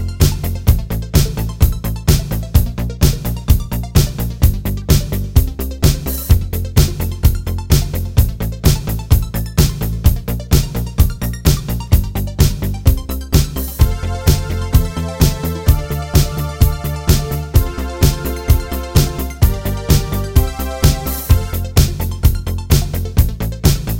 Cut Down Disco 3:39 Buy £1.50